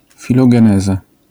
wymowa: